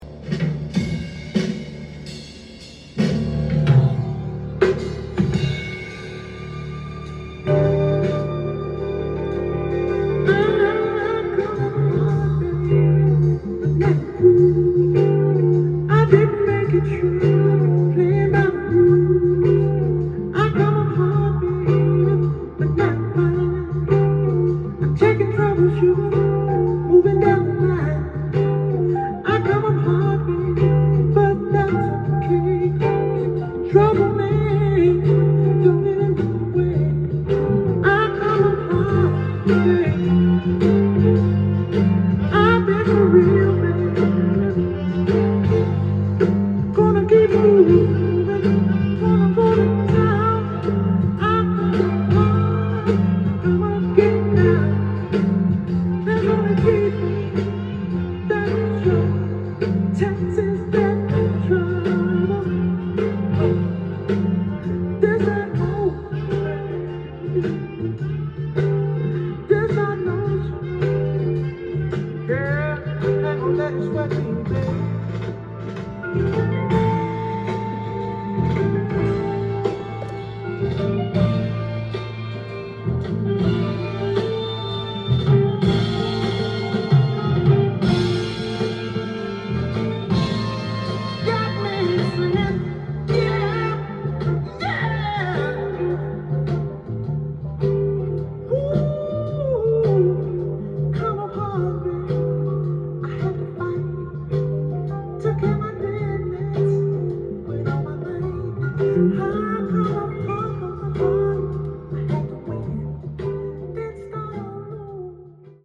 ジャンル：FUNK
店頭で録音した音源の為、多少の外部音や音質の悪さはございますが、サンプルとしてご視聴ください。